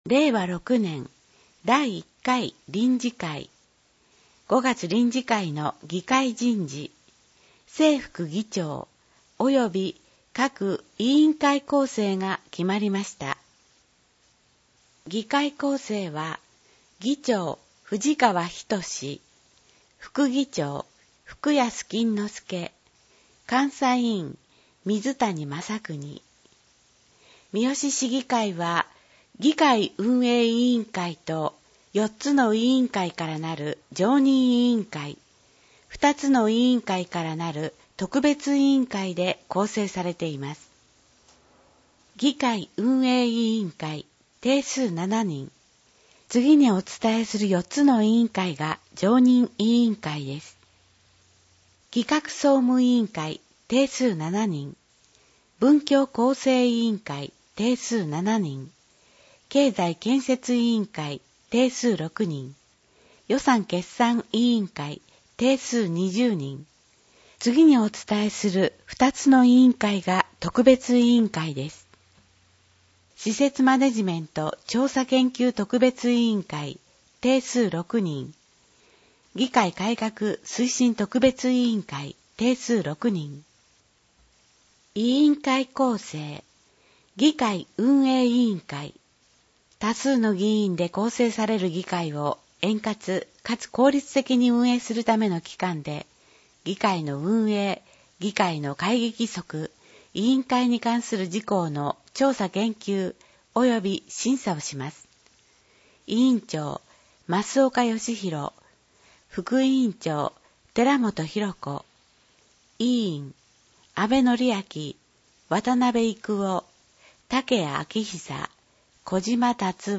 『声の議会広報』は、「みよし議会だより きずな」を音声情報にしたもので、平成29年6月15日発行の第110号からボランティア団体「やまびのこ会」の協力によりサービス提供をはじめました。（一部AI自動音声（テキスト読み上げ）ソフト「VOICEVOX Nemo」を使用）